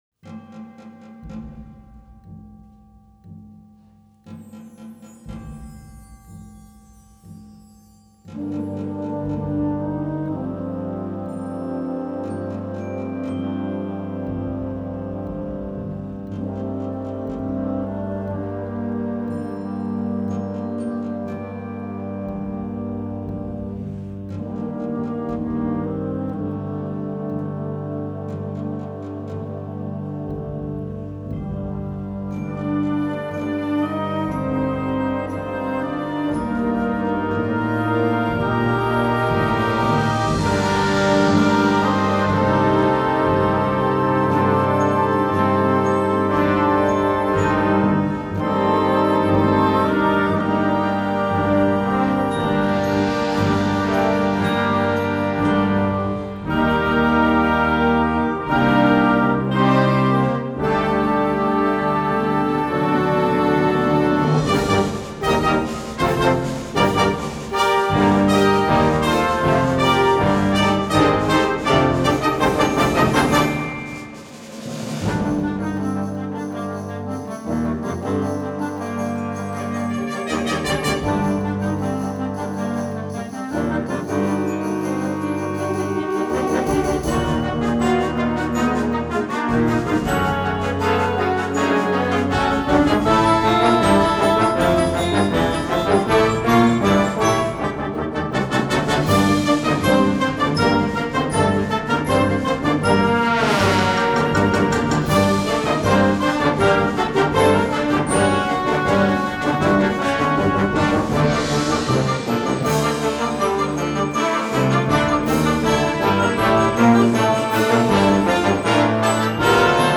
Concert Band
Instrumental Concert Band Concert/Contest